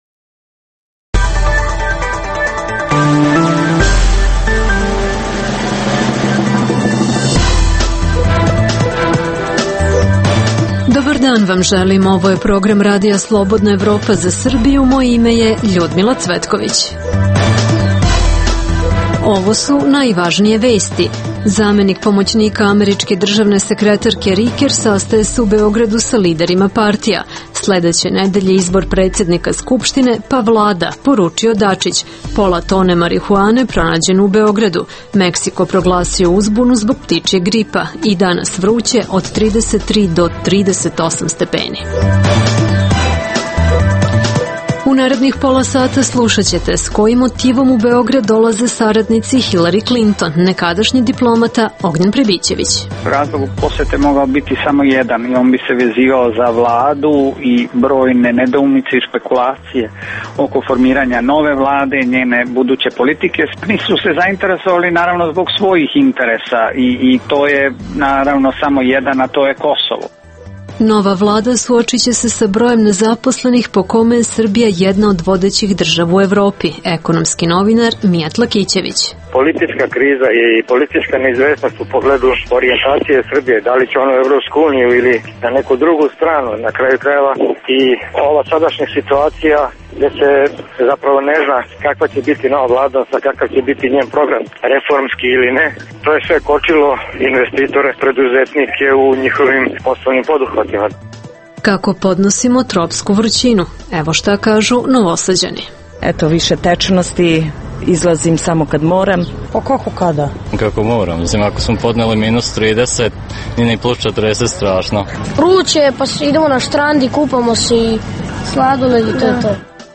Kako građani podnose visoku temperaturu pitali smo Novosađane.